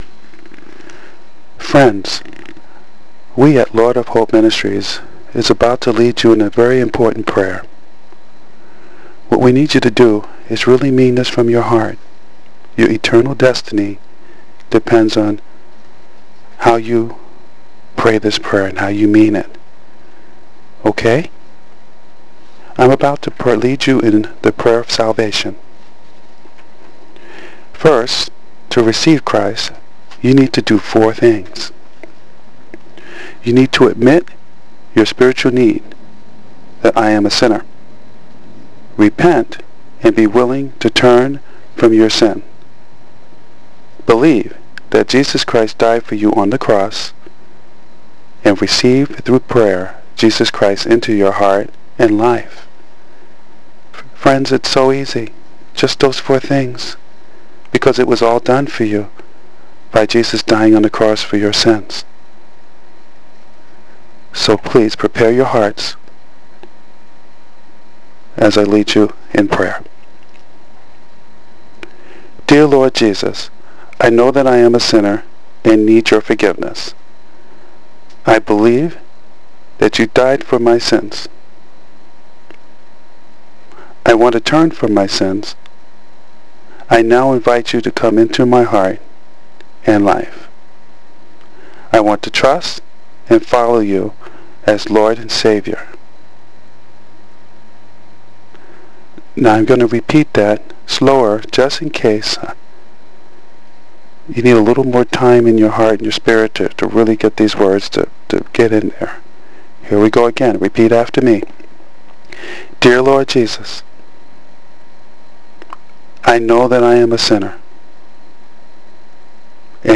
Friends these come straight from the Pastors of Lord Of Hope Ministries.